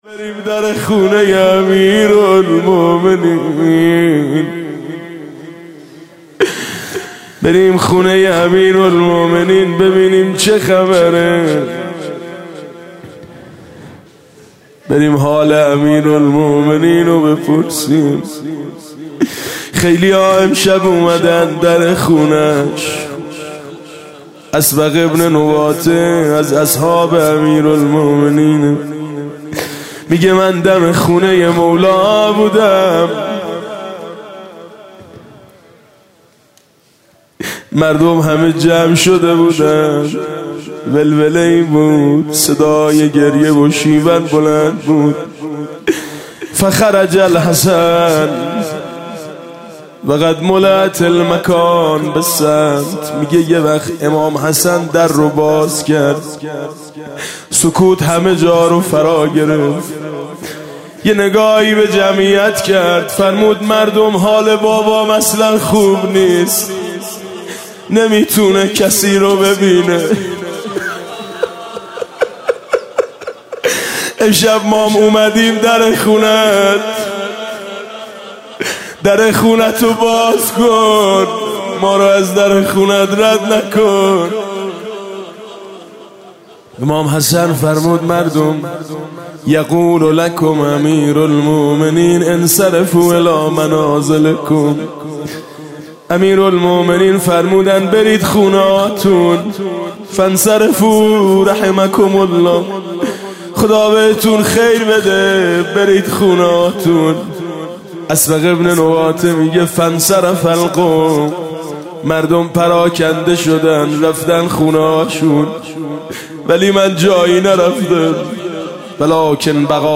21 رمضان 97 - هیئت میثاق با شهدا - روضه - رنگت پریده است پدر جان